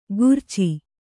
♪ gurci